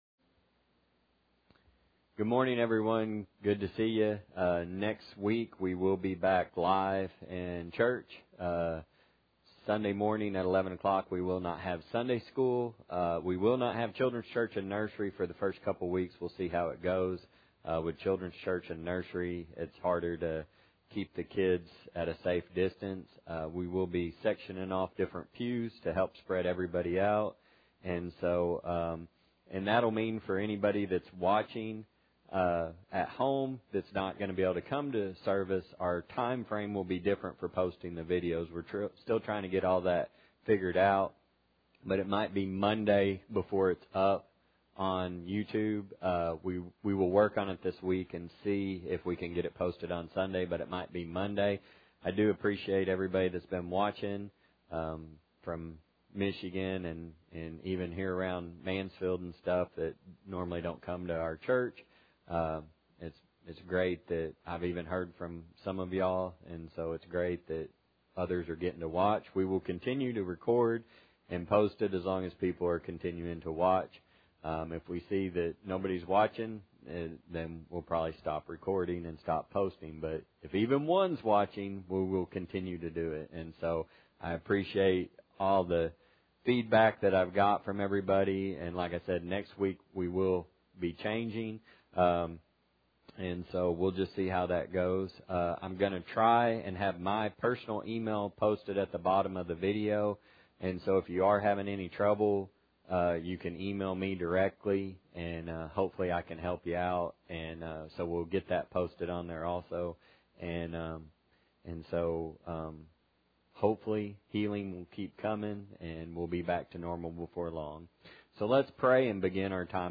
1 Thessalonians 5:1-11 Service Type: Sunday Morning Audio Version Below